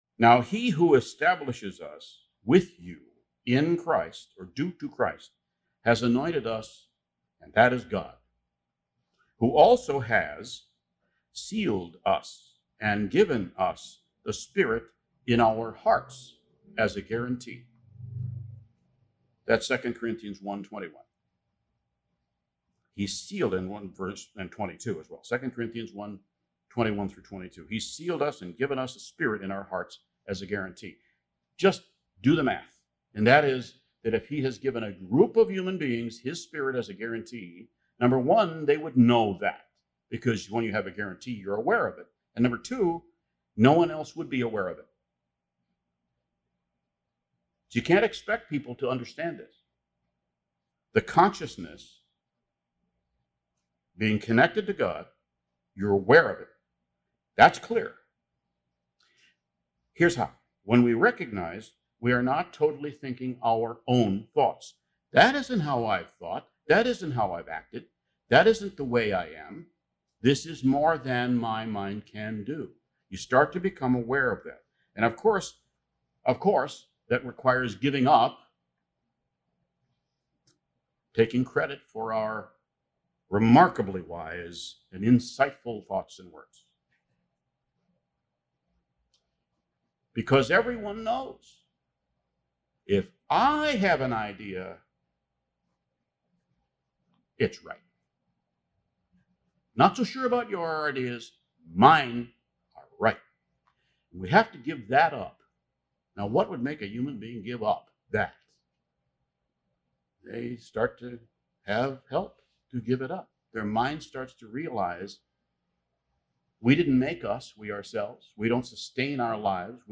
Given in Beloit, WI